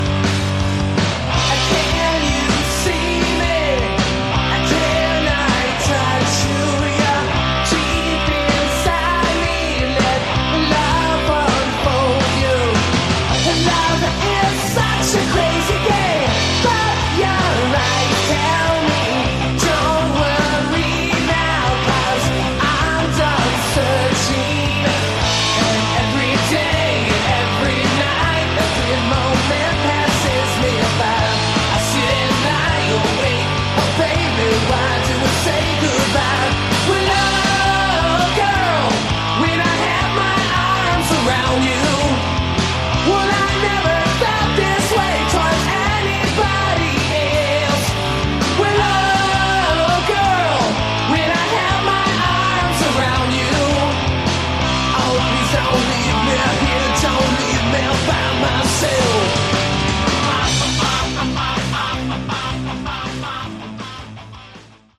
Category: Hard Rock
Lead Vocals, Rhythm Guitars
Lead Guitars
Bass
Drums
Good songs, shame about the production.